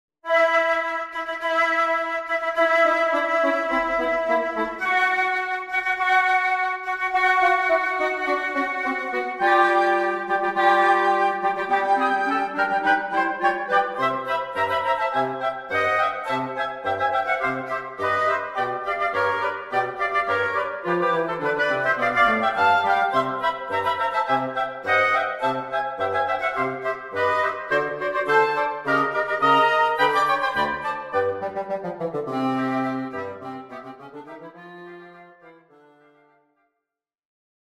Woodwind Quartet